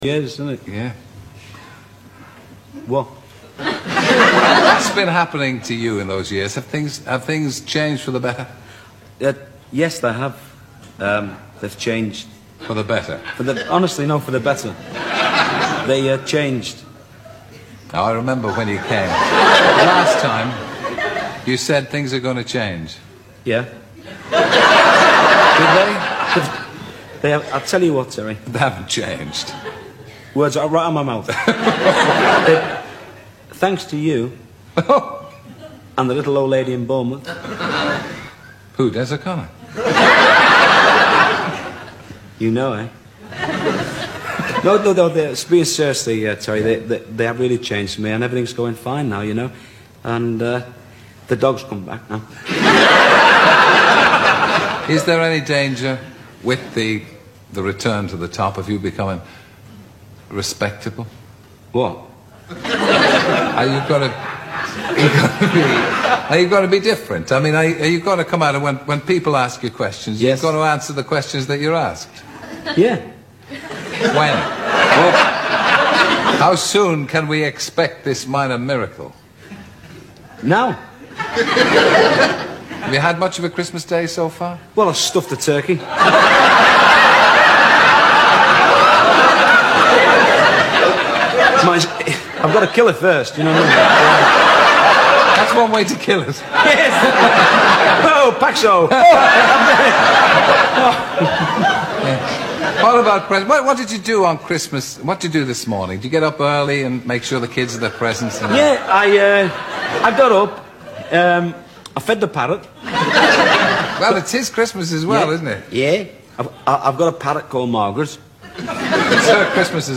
Wogan Interviews Freddie Starr Christmas